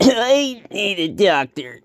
Category 🗣 Voices
cough funny funny-voice hurt injured male man pain sound effect free sound royalty free Voices